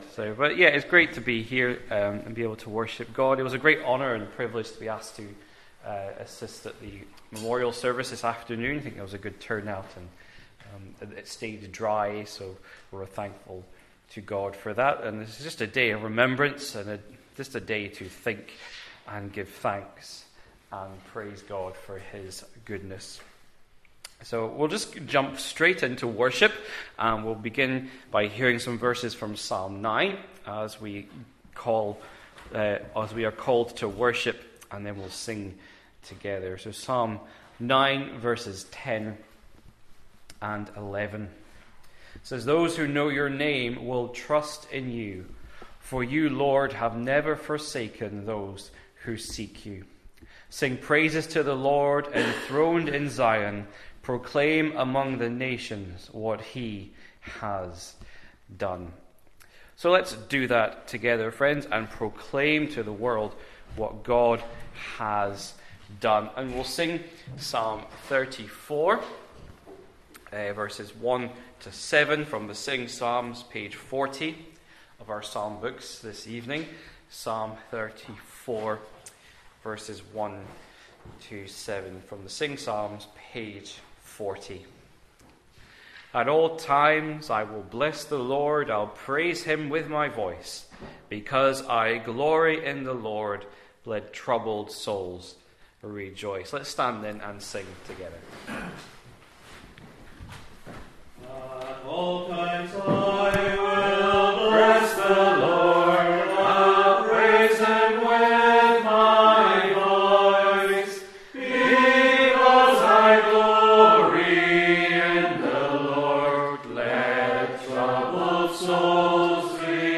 Sunday-Sevrice-6pm-10th-Nov.mp3